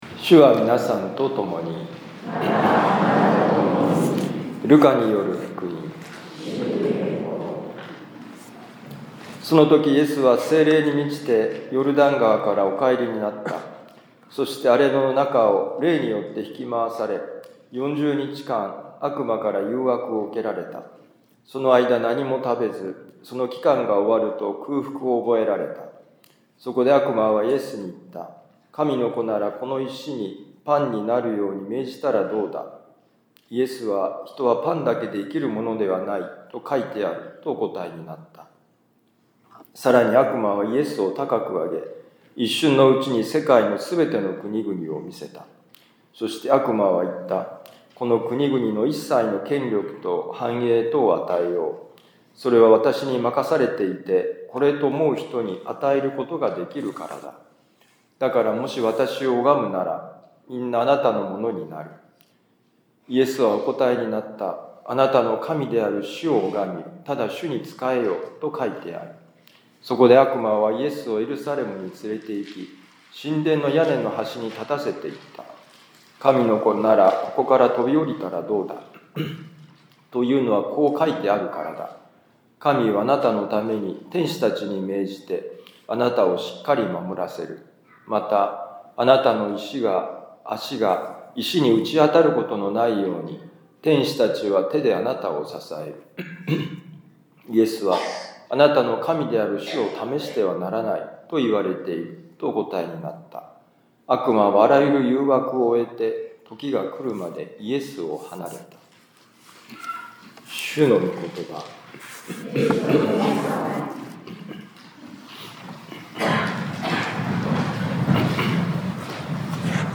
【ミサ説教】
ルカ福音書4章1-13節「三番目の誘惑」2025年3月9日四旬節第１主日ミサ六甲カトリック教会